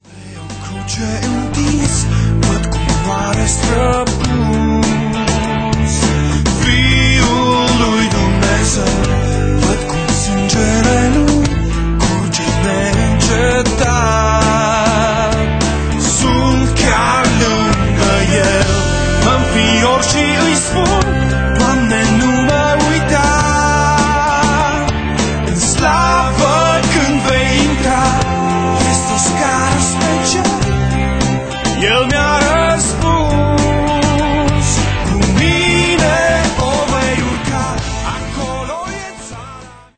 Abordand stilul pop-rock intr-o maniera proprie